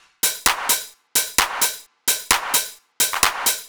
Downtown House/Loops/Drum Loops 130bpm